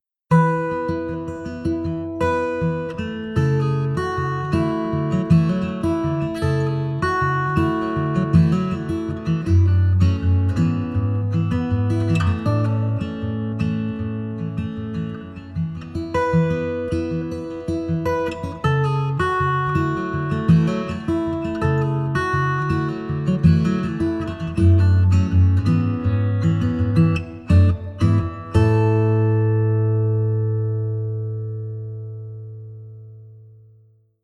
Eastman Dreadnought
Ma première guitare folk, là encore un coup de coeur.
Un super son , chaud et brillant, assez creusé, beaucoup de sustain , des finitions soignées.